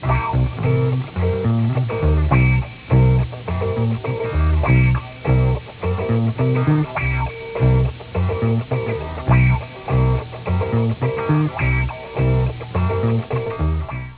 Super Match think music